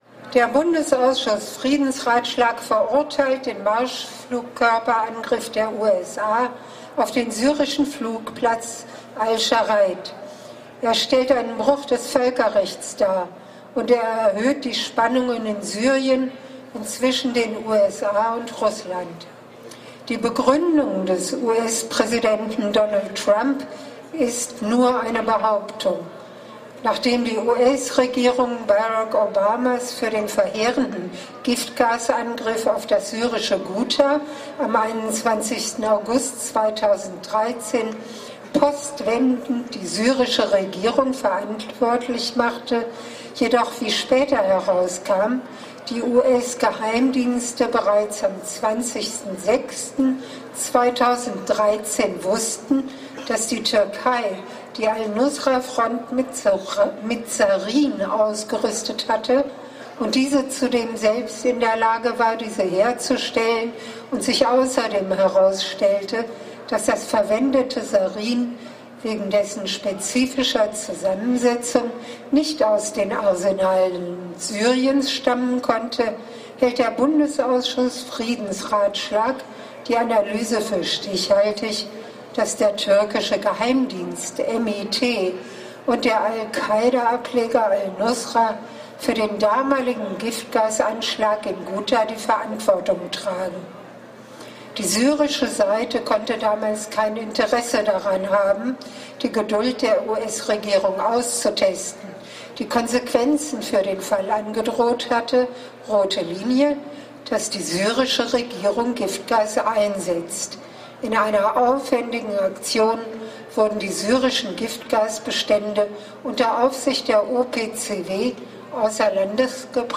Kapitel 2: Die Kundgebung
Hier findet ihr das Bühnenprogramm der Kundgebung mit den Ansprachen und musikalischen Darbietungen auf dem Marktplatz[3] in der Düsseldorfer Altstadt.